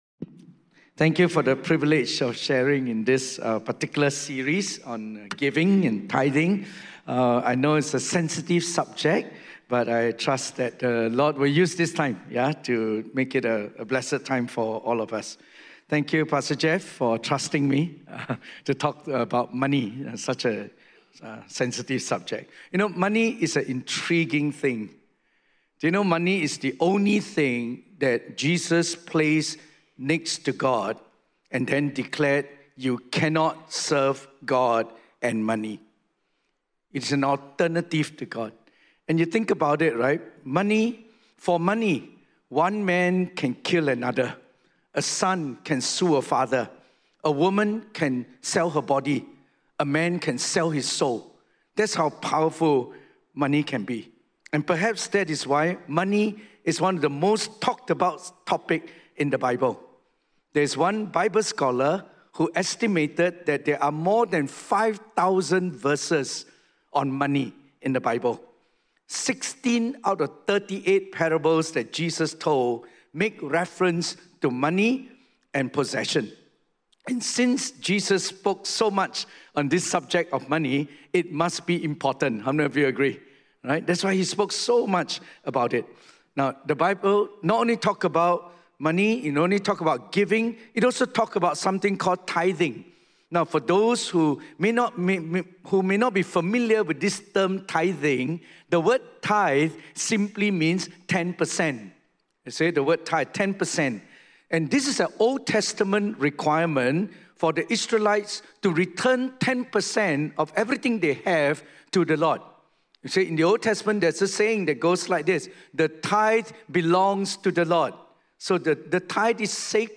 Through biblical teaching and practical conversation, we’ll tackle real questions many people quietly ask, and consider how faith, finances, and trust intersect in everyday life.